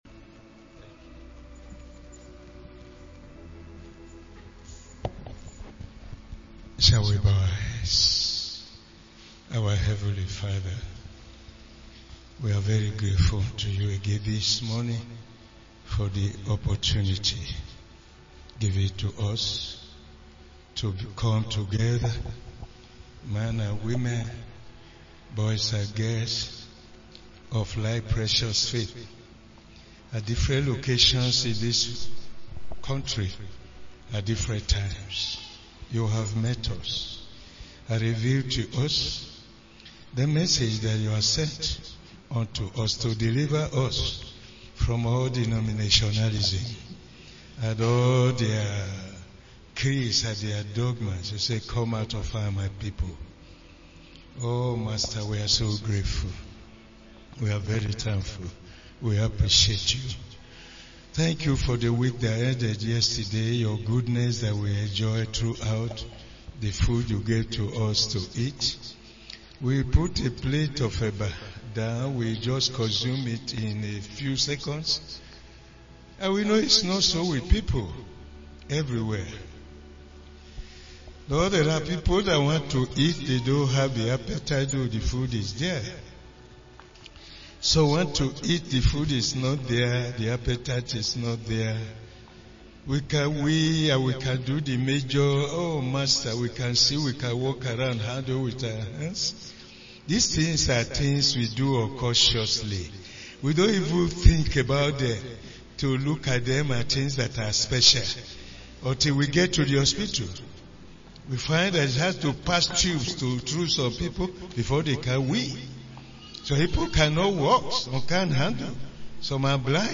SUNDAY MAIN SERVICE